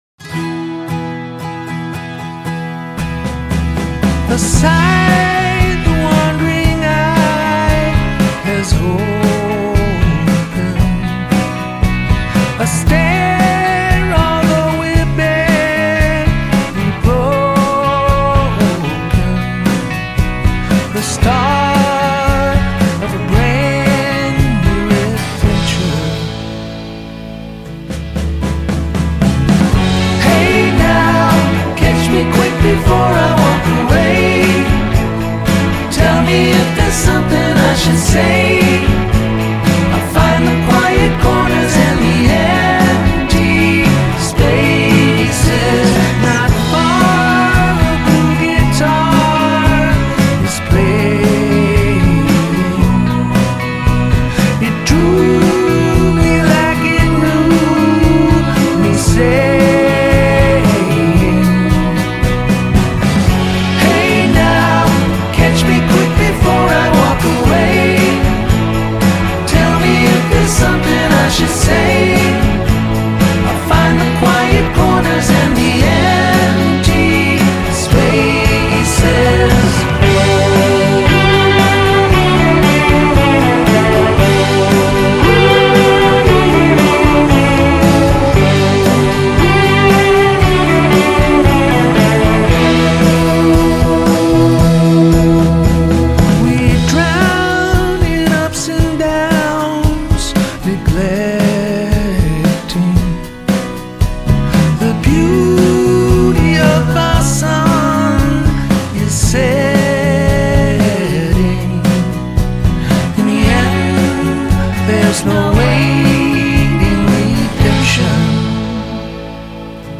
It is quite possibly the perfect pop song.
a three-part harmony design